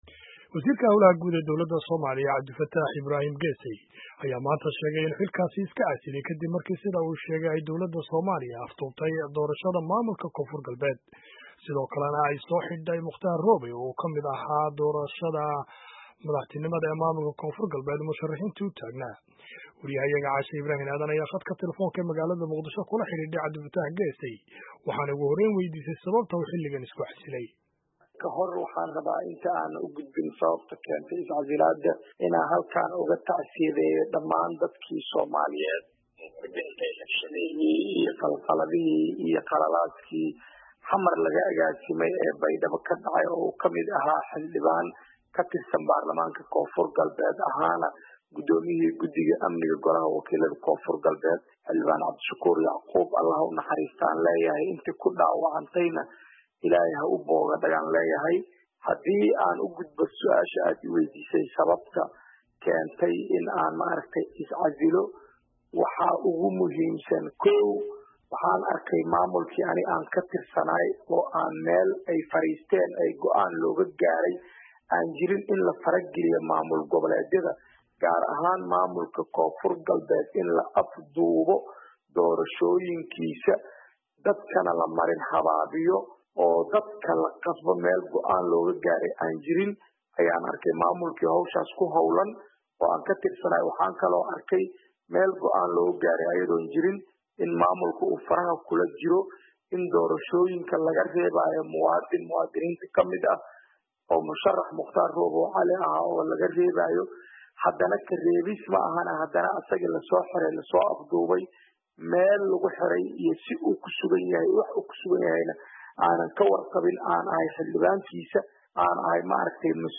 Dhageyso Wareysiga Wasiir Geesey